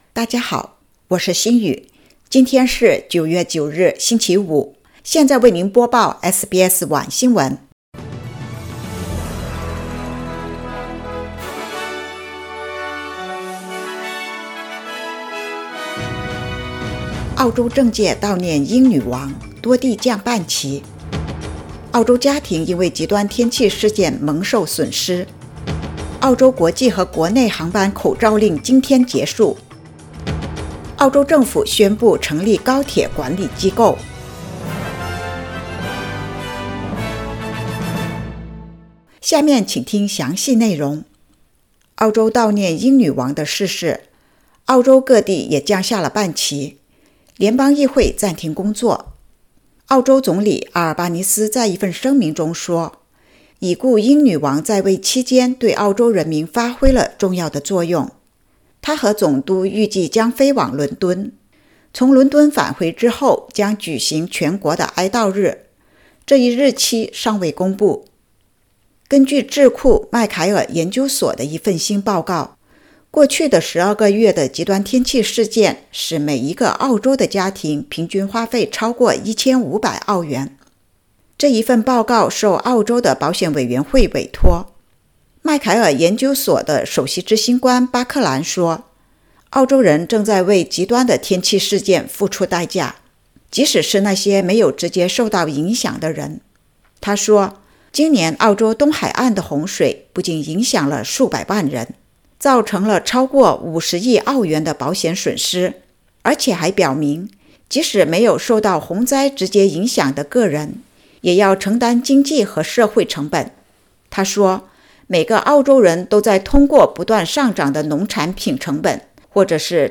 SBS晚新闻（2022年9月9日）
SBS Mandarin evening news Source: Getty / Getty Images